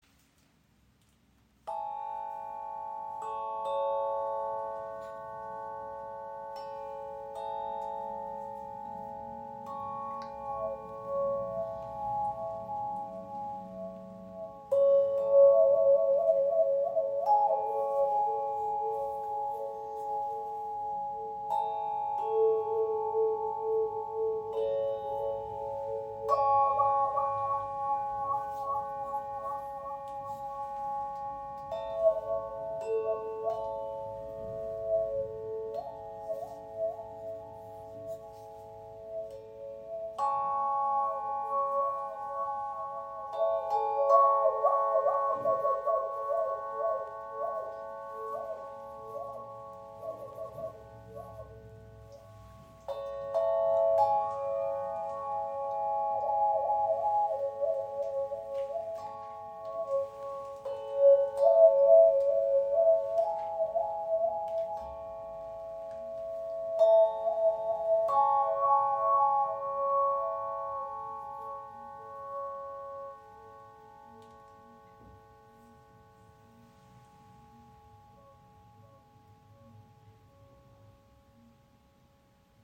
Wayunki – Beruhigende Klänge für Meditation und Klangheilung • Raven Spirit
Seit 2018 verbreiten diese Klangkörper eine tiefe, beruhigende Resonanz. Gefertigt aus Aluminium und mit einer schützenden Eloxalschicht veredelt, sind sie langlebig und farblich vielfältig. Durch die intuitive Spielweise entstehen sanfte Melodien, ganz ohne musikalische Vorkenntnisse.
Einmal angespielt, entfaltet sie ihre volle Tiefe – beruhigend, inspirierend und voller Lebendigkeit.